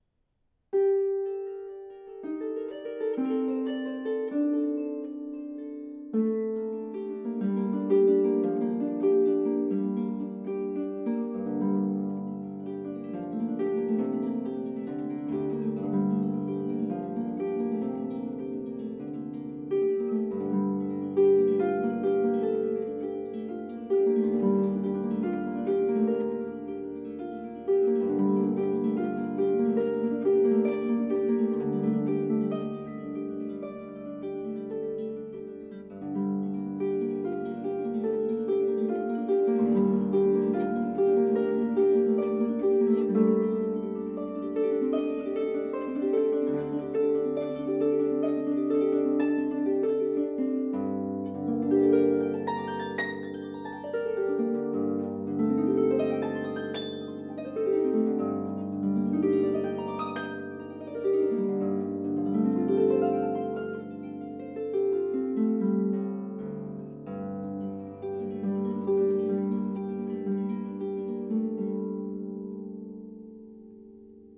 Acoustic Celtic harp...
The biggest issue is I'm capturing so much clicky clacky finger noise, and the actual harp sounds dull, fuzzy -- just not clear and resonant.
Mics are matched Rode NT5.
OK, here's the raw mp3 test. I think the mics were about 42" away in AB for this one.